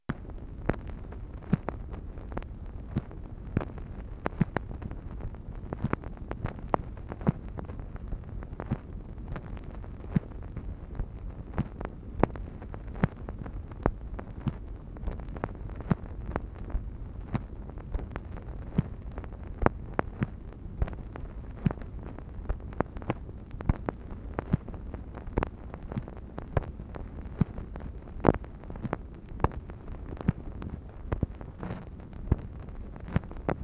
MACHINE NOISE, January 9, 1964
Secret White House Tapes | Lyndon B. Johnson Presidency